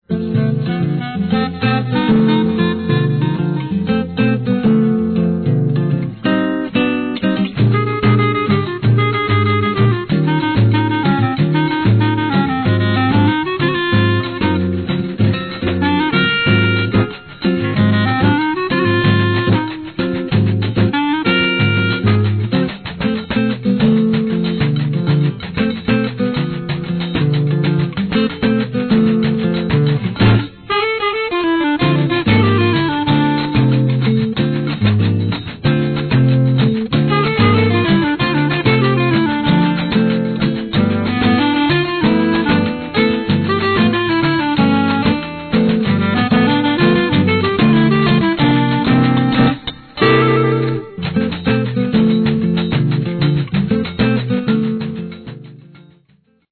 festive and emotional music